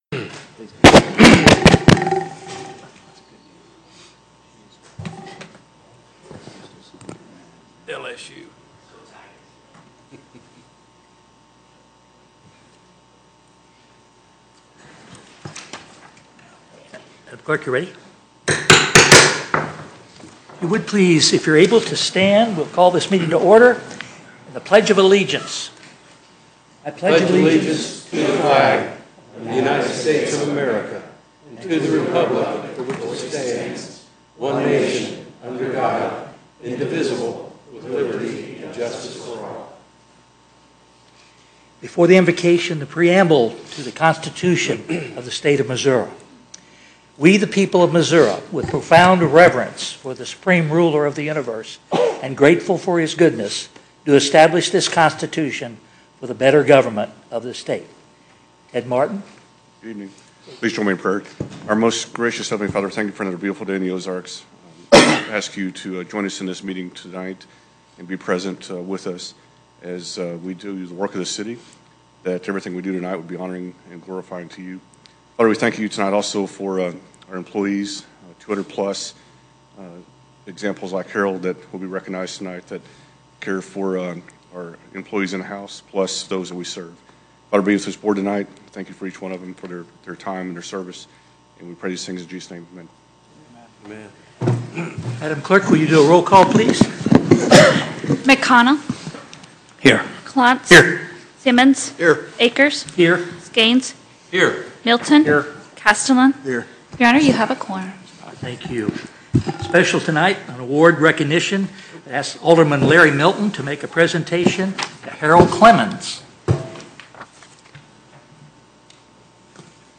Agenda for the January 28, 2020 Regular Meeting of the Board of Aldermen.